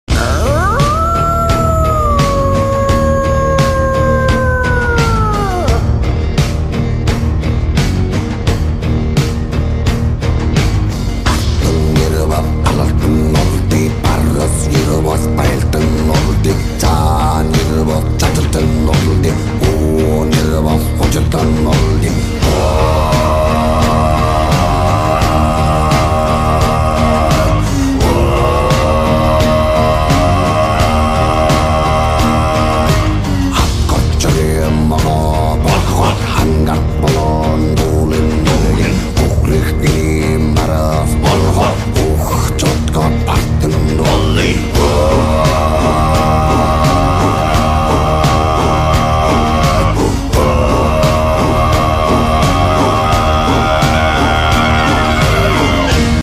/ Mongolian Khoomei sound effects free download